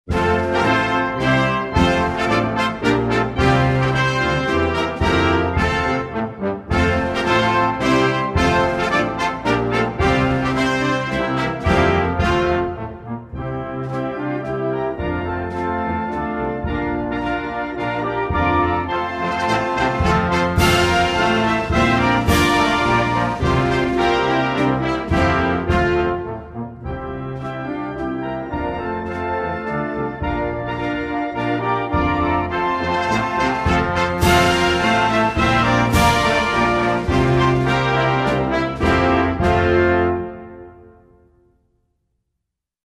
Музыка без слов польского гимна